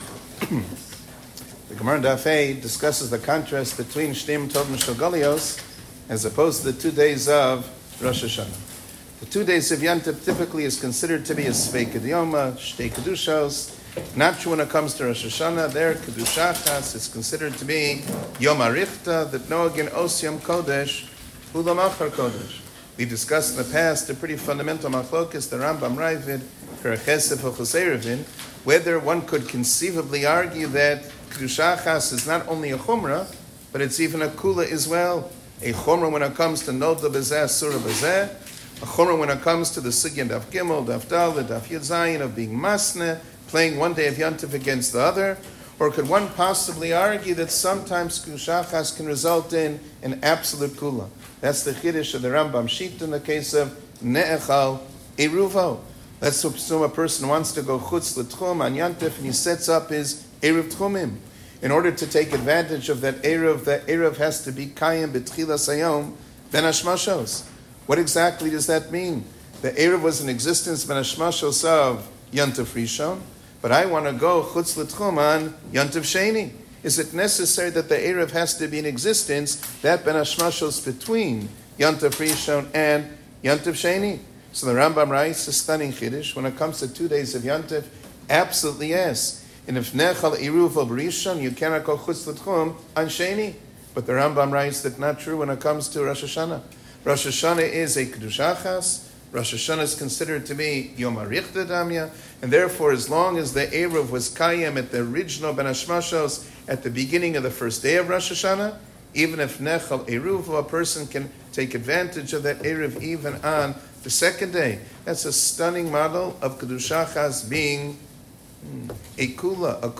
שיעור כללי - דבר שבמנין צריך מנין אחר להתירו